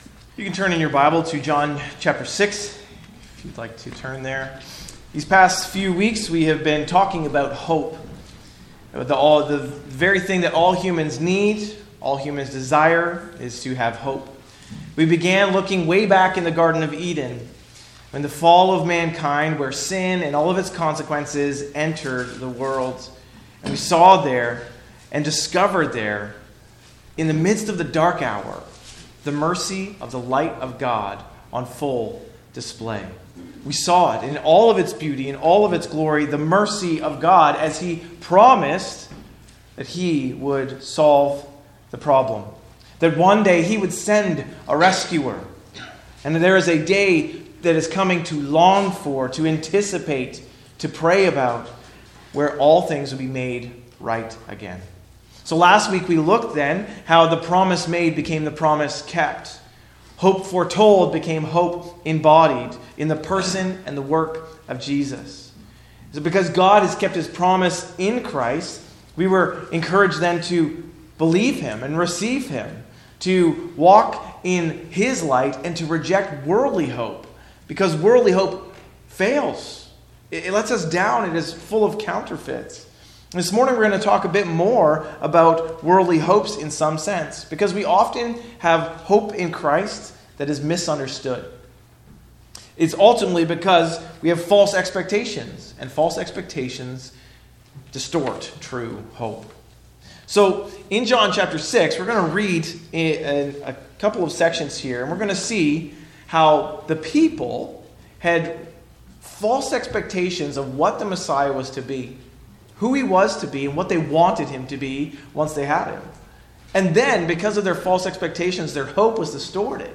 A message from the series "Christ Our Hope."